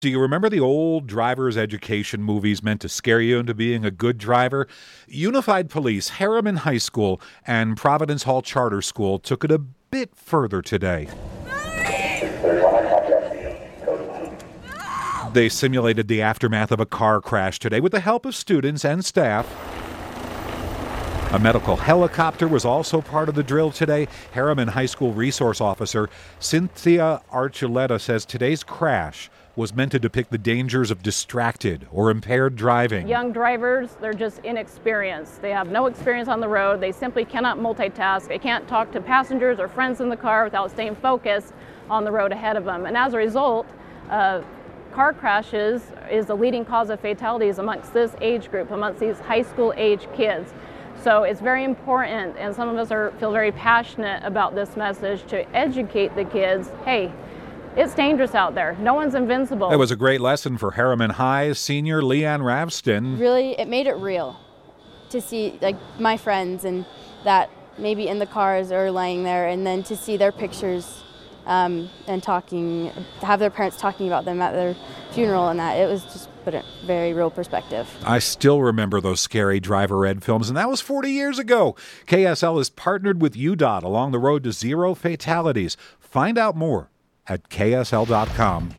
Students screamed and and yelled and a medical helicopter arrived on Herriman High School's campus today as part of mock crash. It was meant to represent the dangers of distracted or impaired driving and it seemed to have an affect on at least one student.